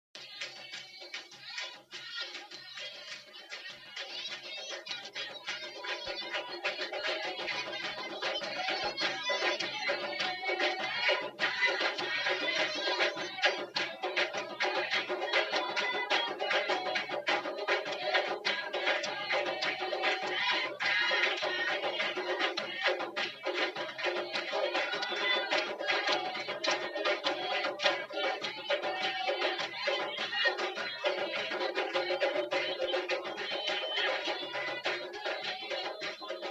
Cantiga abertura do capítulo Identidade Quilombola.mp3